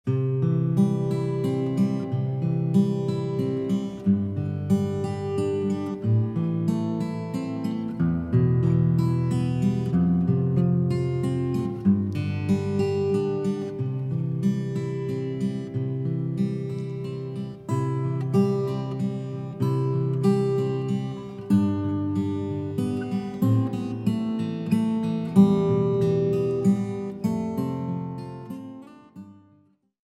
彼らの目指すクラッシクスタイルの新しい解釈・アレンジ・演奏により、新たなる魅力が吹き込まれる。
シドニーフォックススタジオEQで録音され